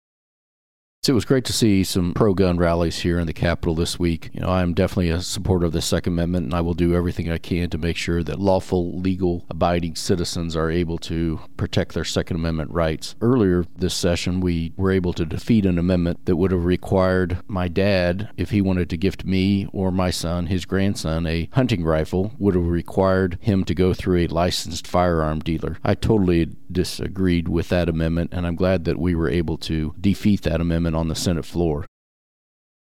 JEFFERSON CITY — State Sen. Denny Hoskins, R-Warrensburg, discusses the Second Amendment and his take on the findings of a Missouri House of Representatives investigative committee.